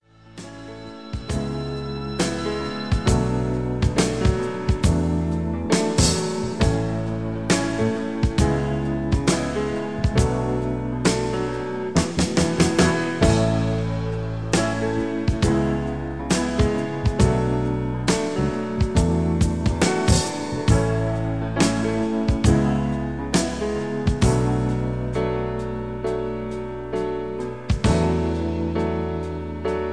(Version-2, Key-A) Karaoke MP3 Backing Tracks
Just Plain & Simply "GREAT MUSIC" (No Lyrics).